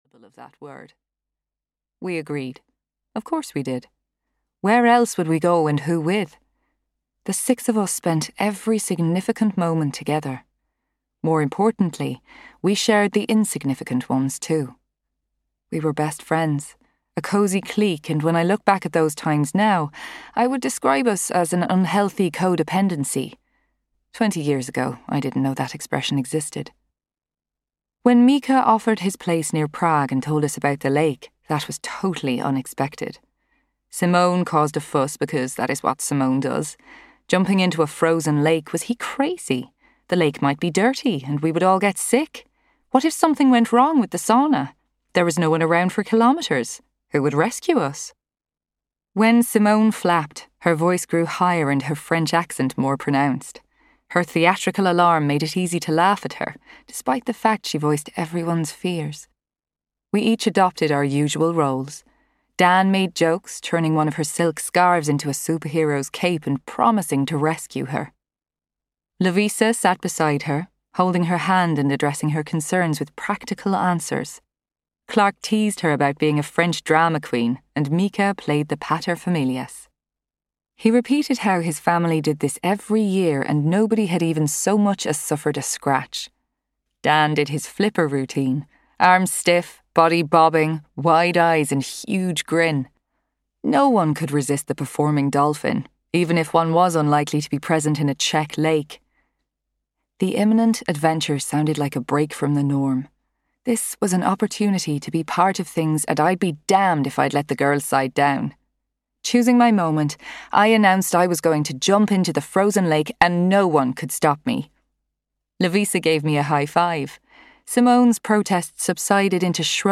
Audio knihaOdd Numbers (EN)
Ukázka z knihy